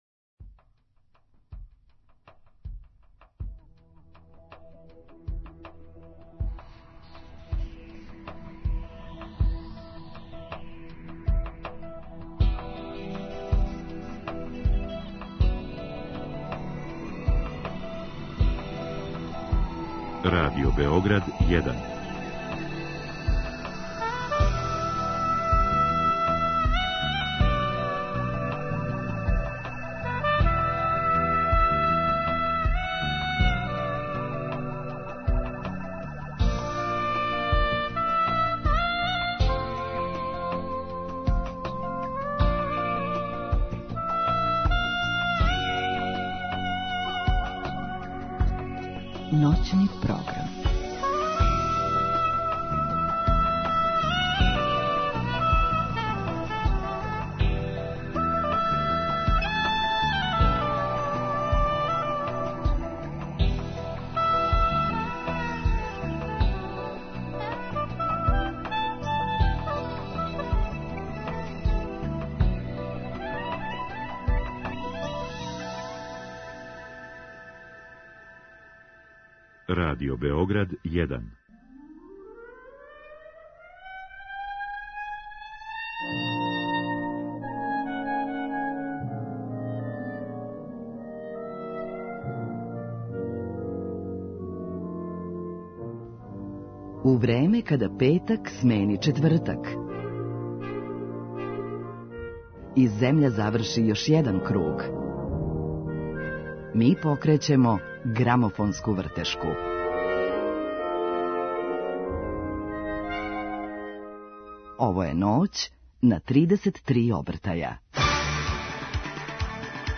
У емисији ћемо имати прилике да чујемо композиције са његовог новог албума и сазнамо како су настајале.